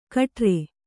♪ kaṭre